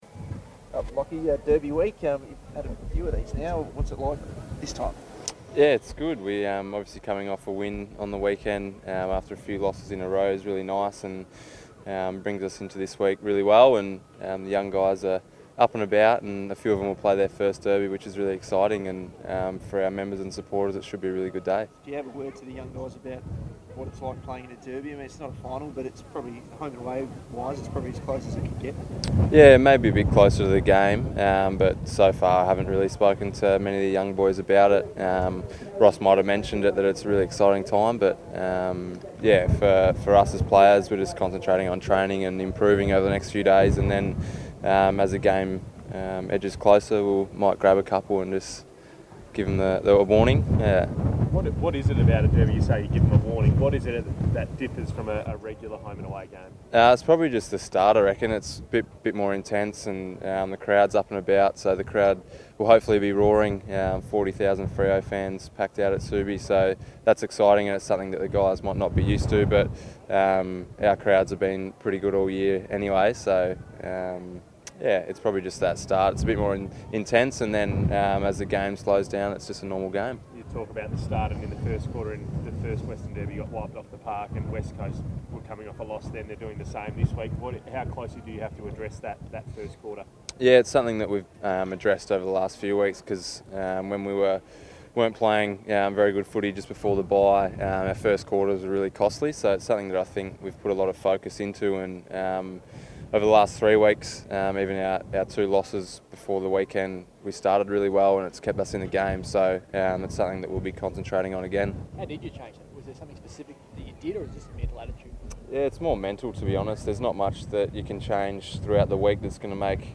Lachie Neale chats to the media ahead of Freo's clash against the Eagles.